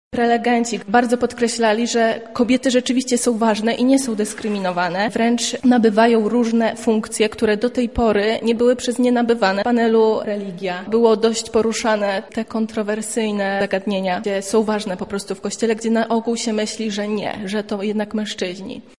Nad tym pytaniem zastanowili się uczestnicy konferencji naukowej na Wydziale Politologii UMCS.